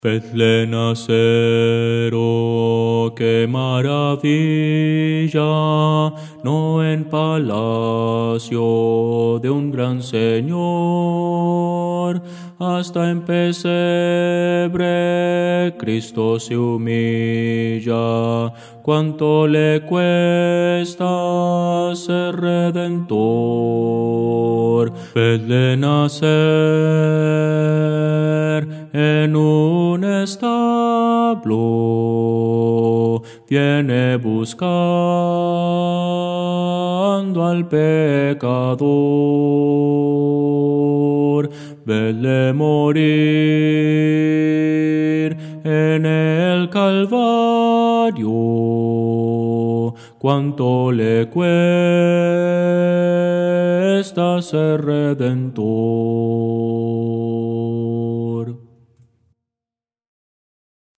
Voces para coro
Bajo – Descargar
Audio: MIDI